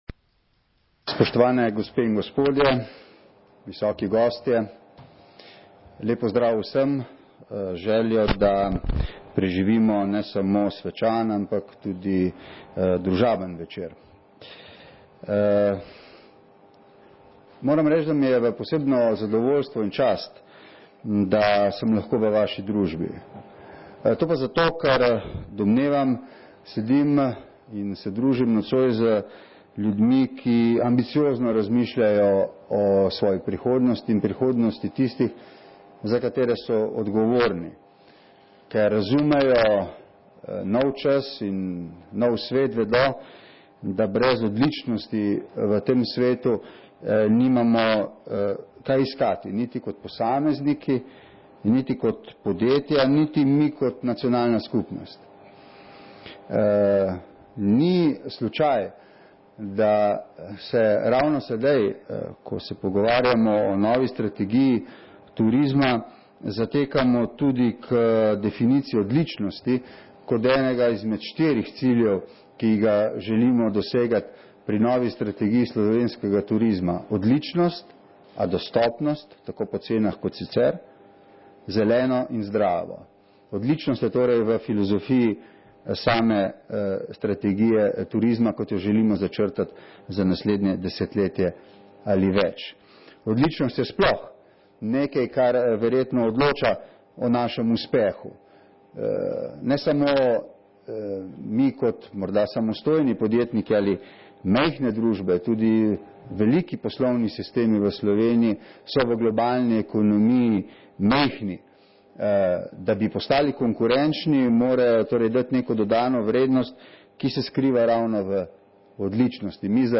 Premier Borut Pahor na slovesnosti ob podelitvi priznanja Republike Slovenije za poslovno odličnost za leto 2009
Predsednik Vlade Republike Slovenije Borut Pahor se je danes pozno popoldne udeležil slovesnosti ob podelitvi priznanj Republike Slovenije za poslovno odličnost za leto 2009, kjer je zbrane nagrajence in goste tudi nagovoril.